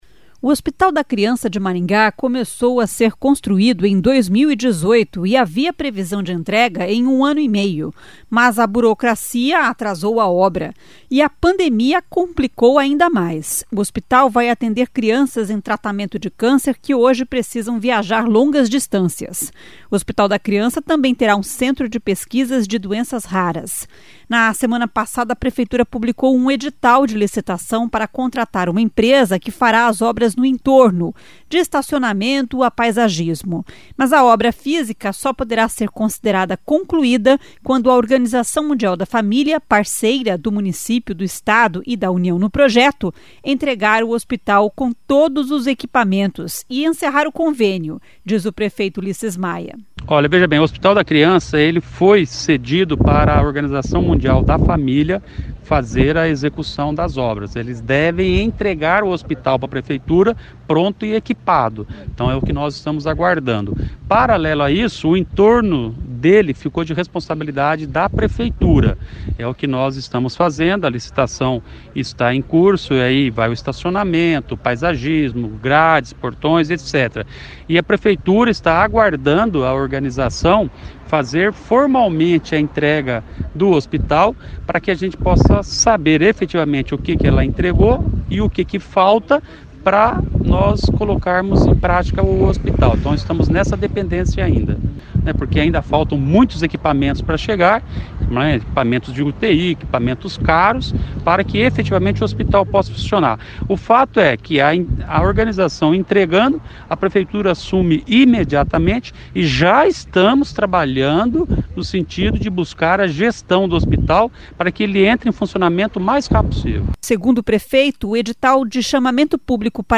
Mas a obra física só poderá ser considerada concluída quando a Organização Mundial da Família, parceira do município, do Estado e da União, no projeto, entregar o hospital com todos os equipamentos e encerrar o convênio, diz o prefeito Ulisses Maia.